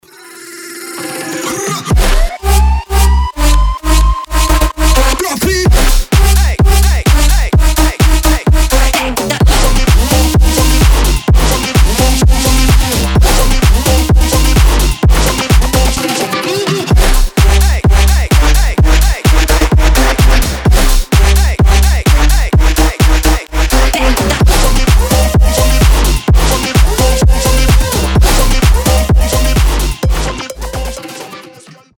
громкие
жесткие
мощные басы
Bass House
взрывные
electro house
сумасшедшие